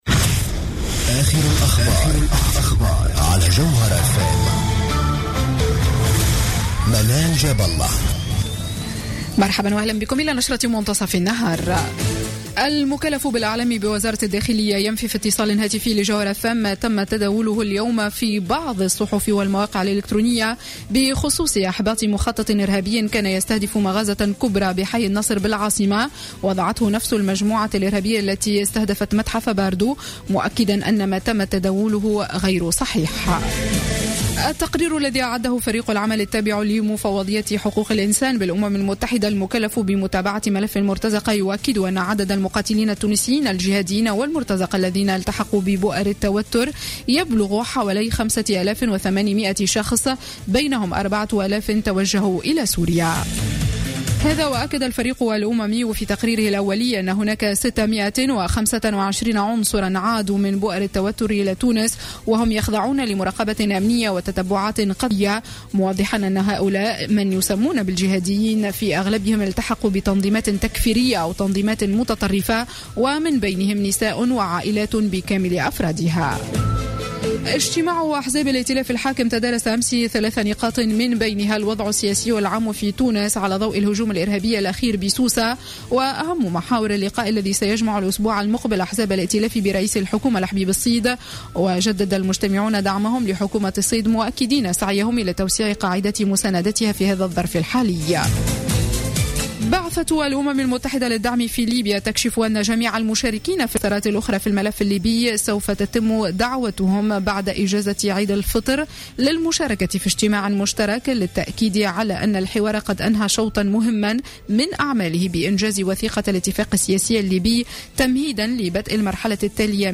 نشرة أخبار منتصف النهار ليوم السبت 11 جويلية 2015